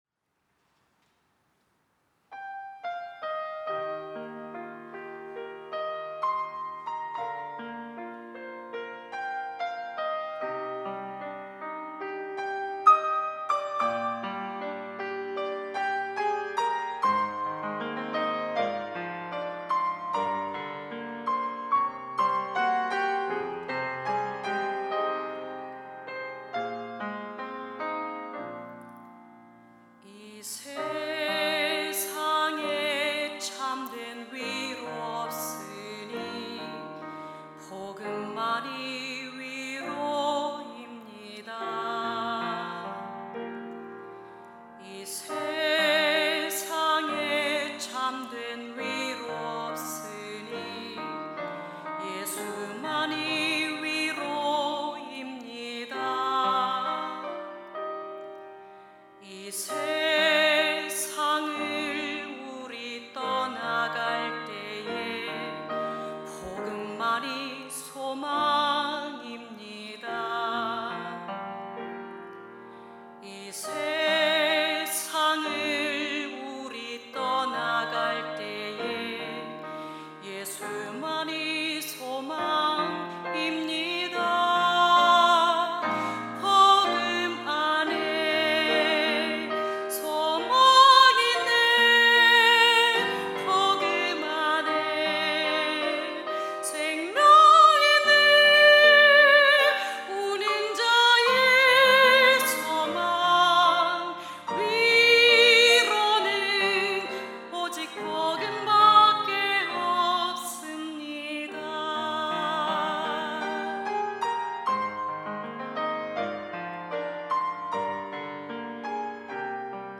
특송과 특주 - 복음 밖에 없습니다